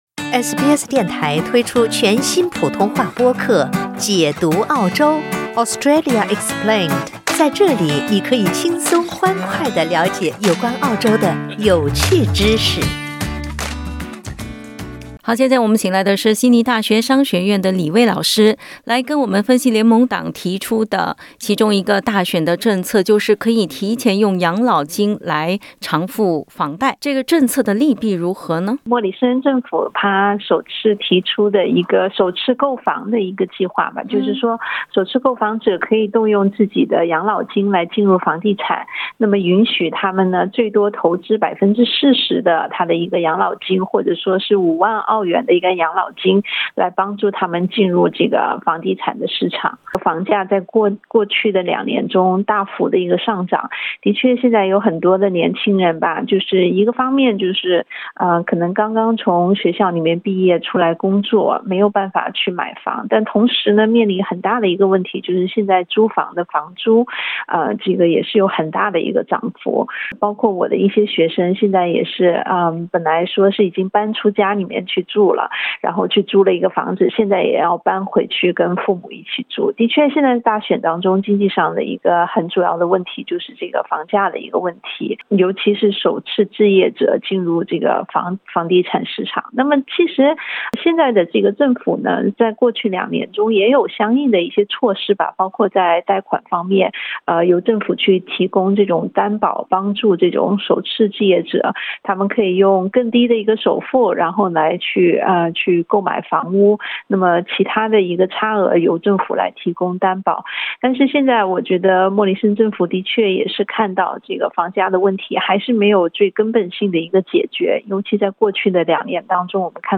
（點擊圖片收聽完整寀訪）